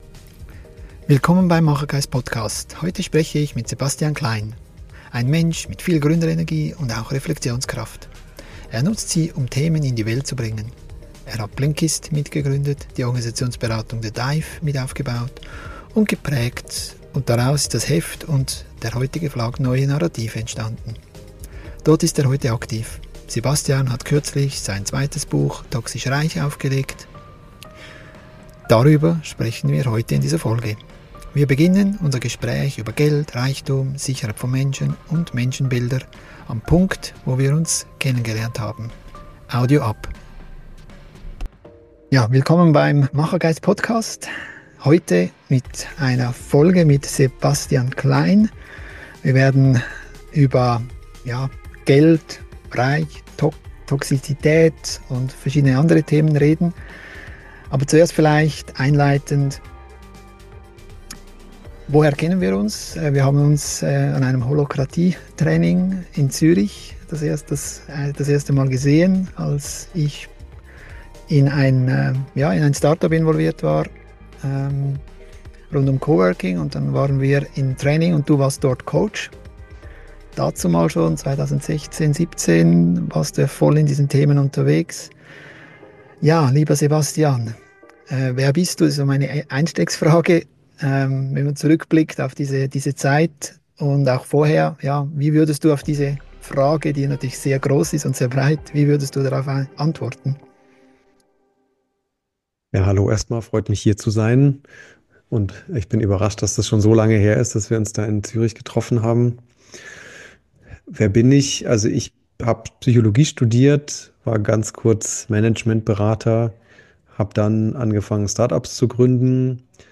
ein Gespräch über Geld, Reichtum, Sicherheit von Menschen und Menschenbilder ~ Machergeist Podcast